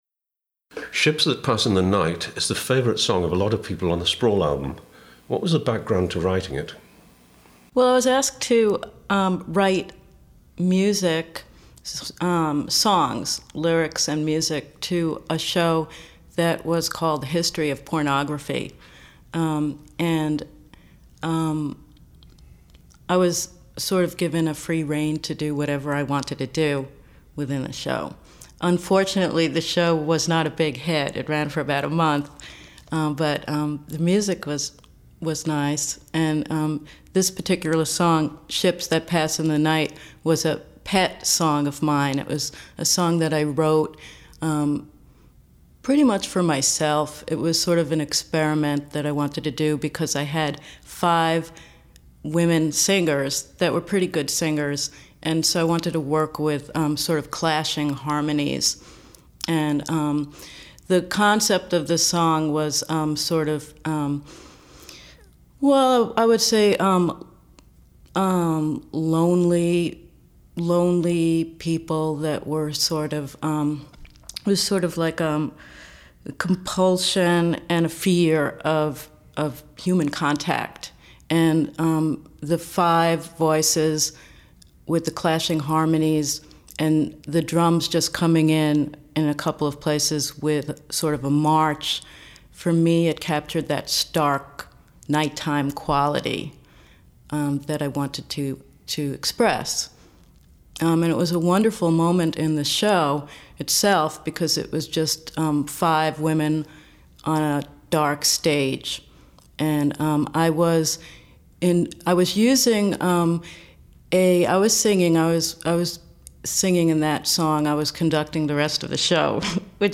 in interview